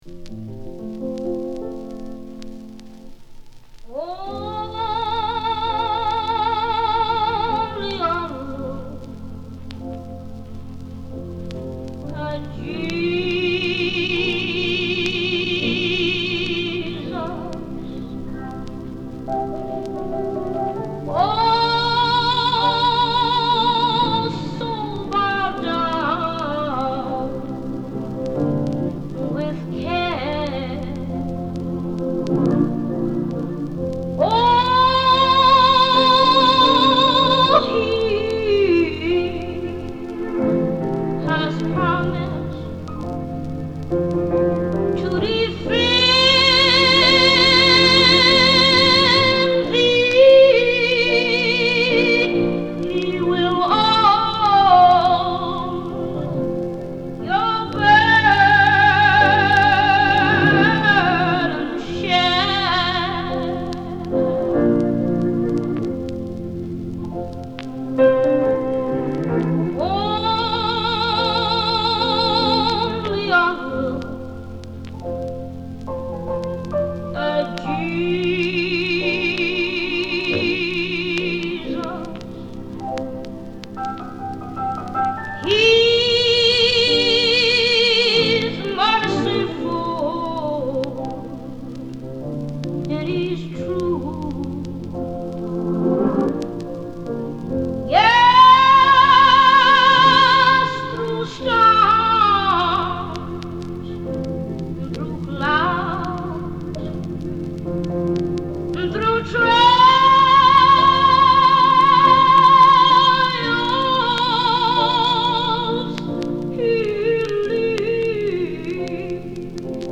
Chicago Gospel